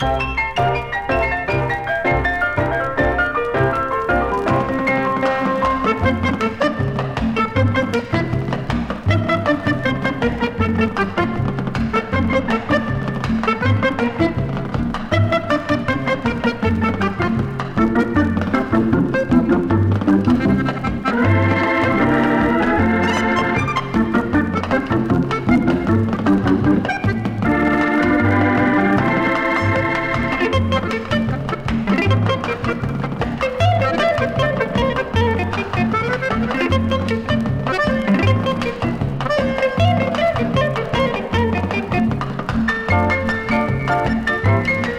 Jazz, Pop, Easy Listening　USA　12inchレコード　33rpm　Stereo